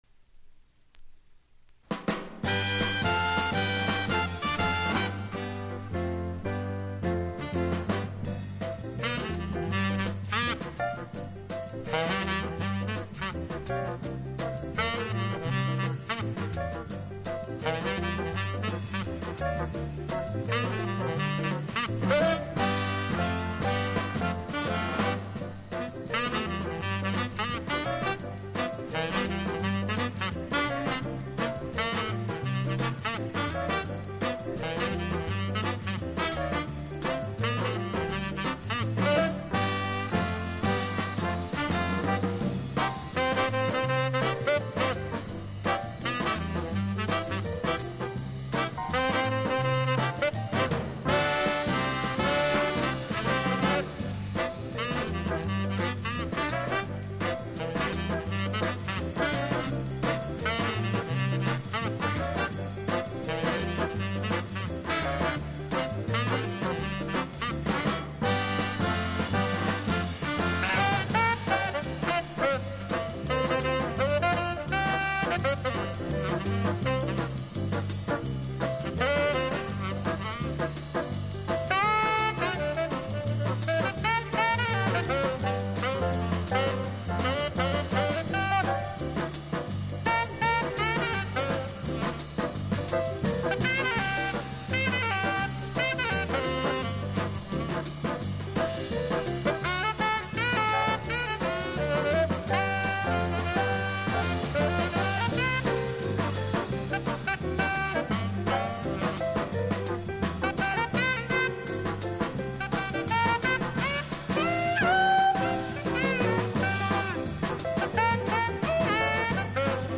（小傷によりチリ、プチ音ある曲あり）
Genre US JAZZ